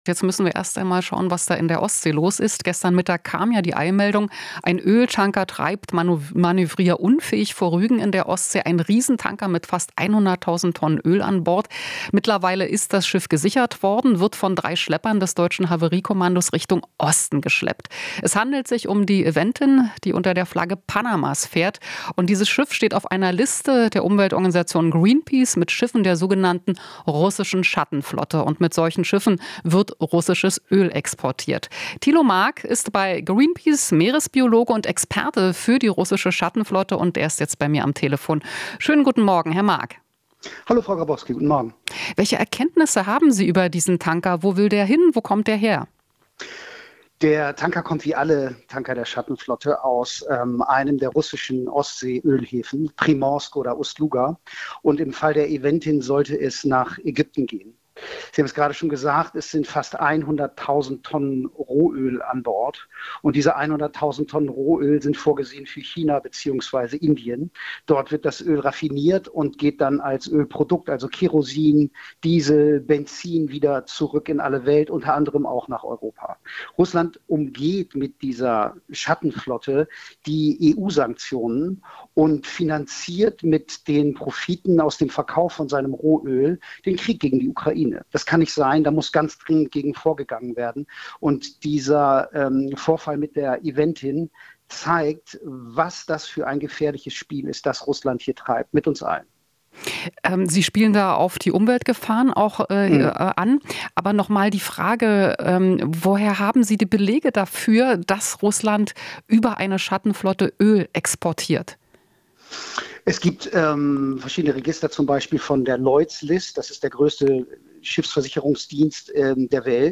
Interview - Greenpeace fordert Maßnahmen gegen Schattenflotte